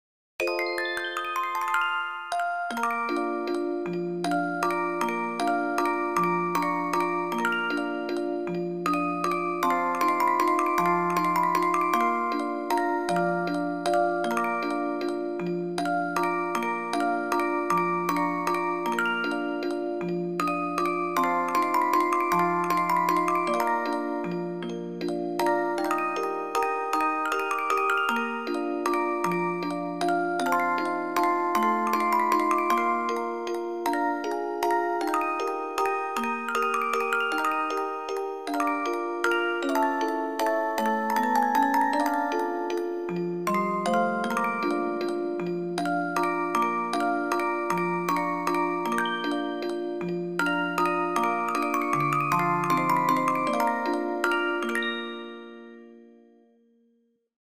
クラシック曲（作曲家別）－MP3オルゴール音楽素材